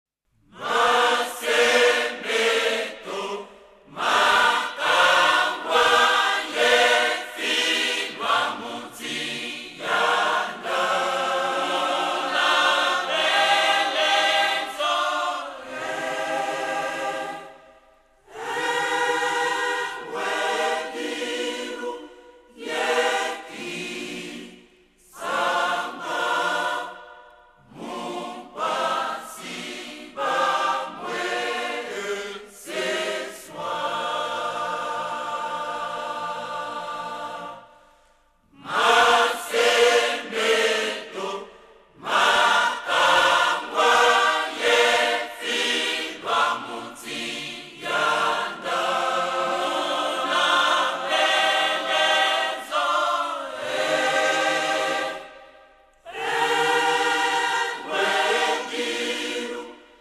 Liste des cantiques